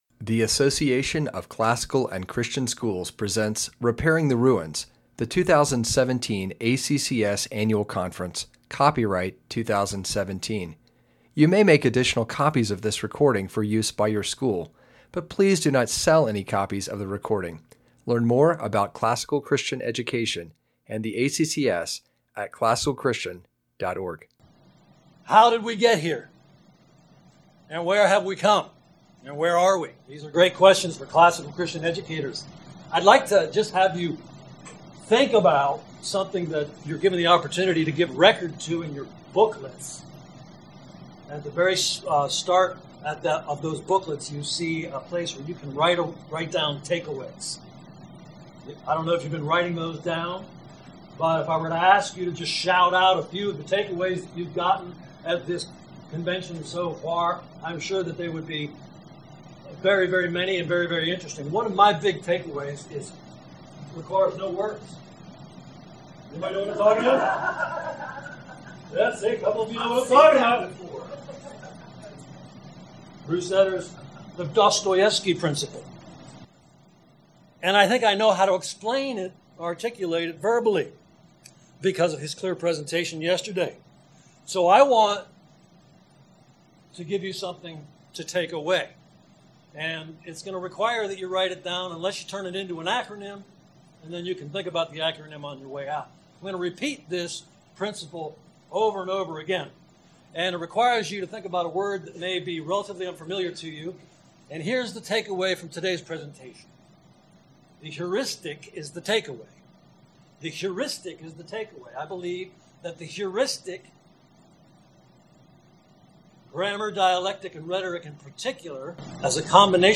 2017 Workshop Talk | 0:56:22 | Teacher & Classroom, Training & Certification
We will learn how to make the progymnasmata serve creative teachers, not the reverse. Speaker Additional Materials The Association of Classical & Christian Schools presents Repairing the Ruins, the ACCS annual conference, copyright ACCS.